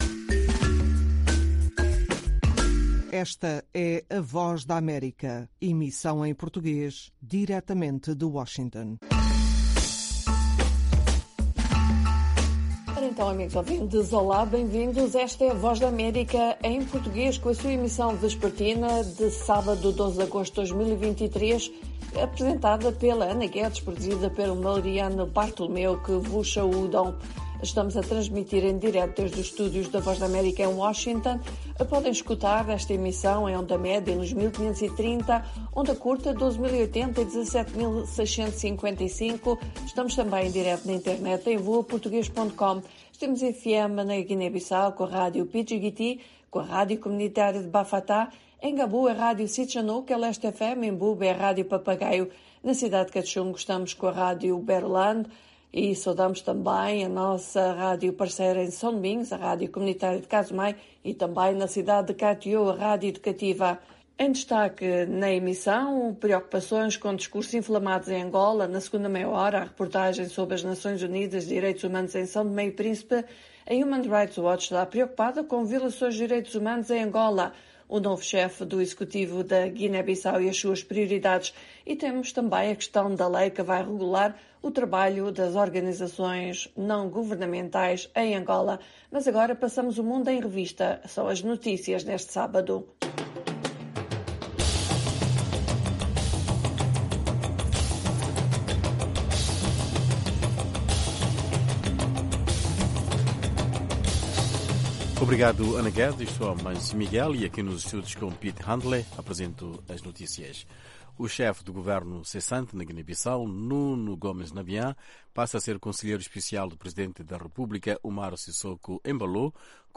Ao sábado discutimos em profundidade um assunto em debate em Angola durante a semana, revimos as melhores reportagens da semana. E as notícias do dia.